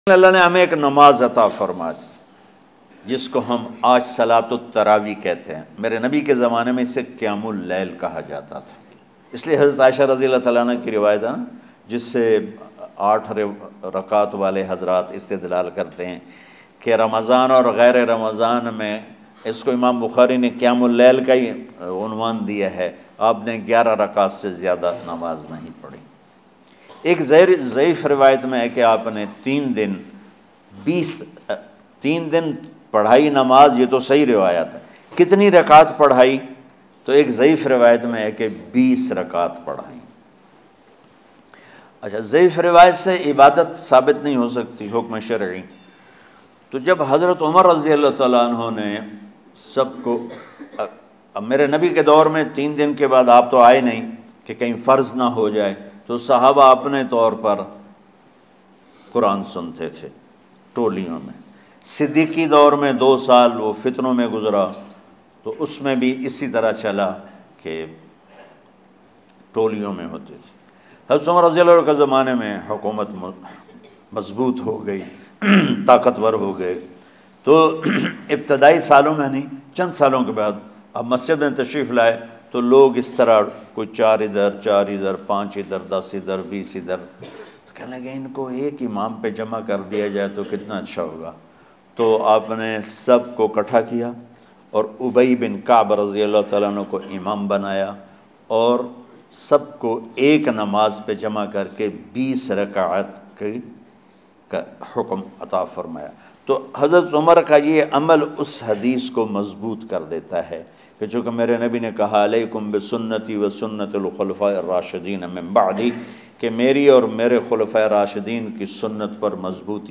Namaz e Taraweeh Molana Tariq Jameel Latest Bayan MP3 Download
Tariq Jameel, commonly referred to as Maulana Tariq Jameel, is a Pakistani religious and Islamic scholar, preacher, and public speaker from Tulamba near Mian Channu in Khanewal, Punjab in Faisalabad, Pakistan.